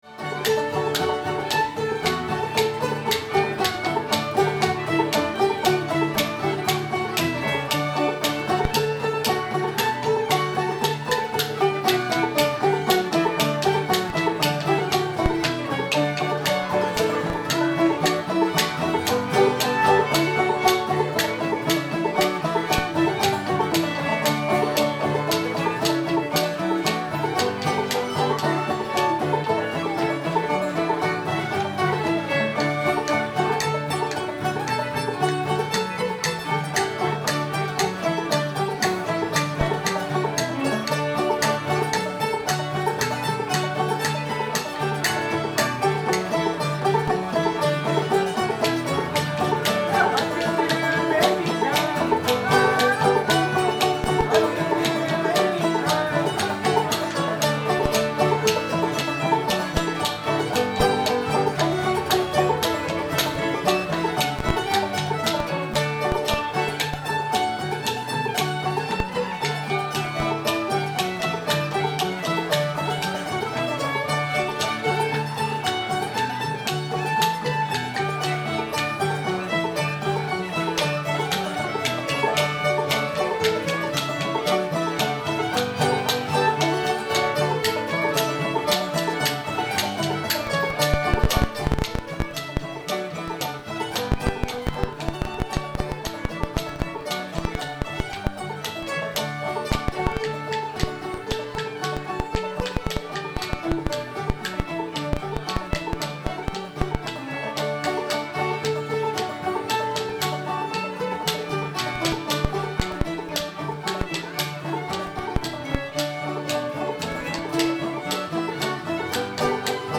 rock the cradle joe [D]